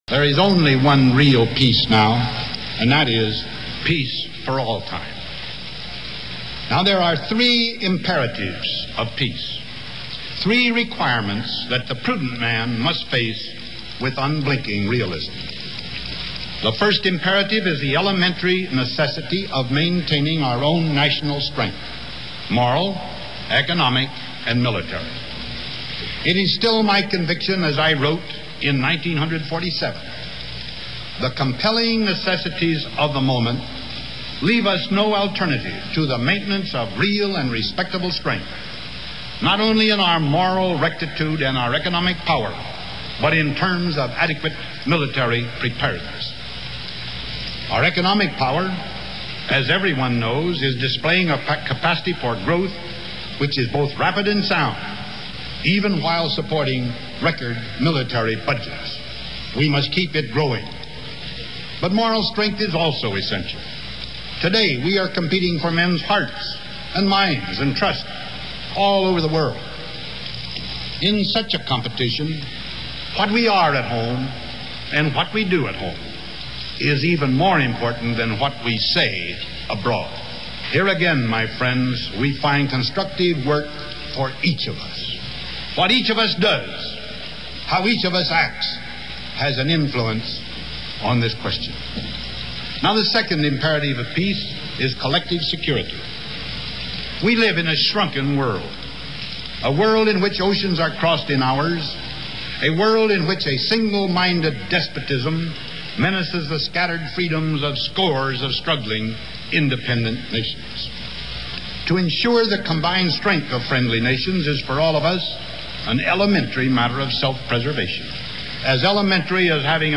Excerpt of U.S. President Dwight D. Eisenhower speaking to the 1956 Republican National Convention